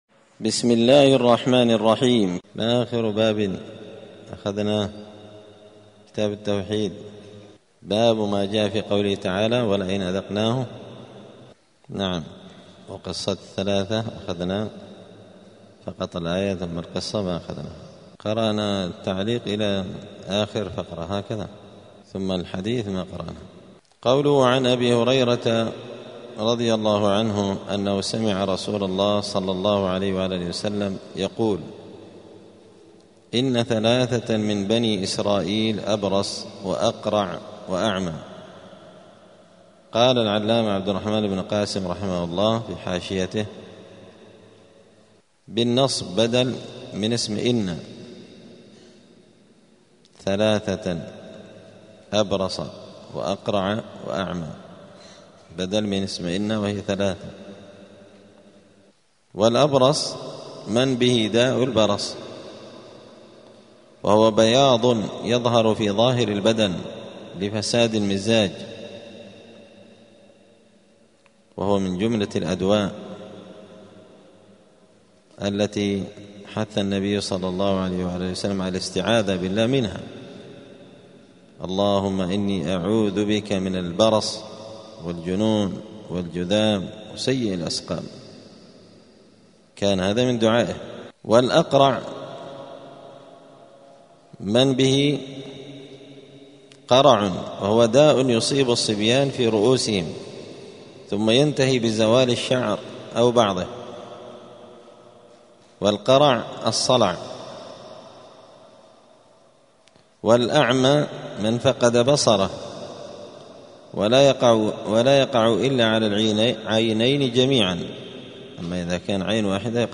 دار الحديث السلفية بمسجد الفرقان قشن المهرة اليمن
*الدرس التاسع والعشرون بعد المائة (129) {تابع لباب ما جاء في قول الله تعالى ولئن أذقناه رحمة منا من بعد ضراء مسته ليقولن هذا لي}*